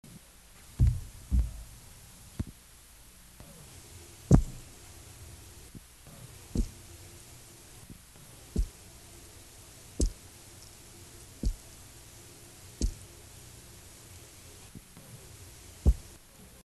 Sounds Made by Epinephelus guttatus
Type of sound produced thumps, escape sounds
Sound production organ swim bladder
Sound mechanism vibration by contraction of associated muscles (probably similar as in Epinephelus striatus)
Behavioural context under duress (handling & electric stimulation)
Remark better quality recording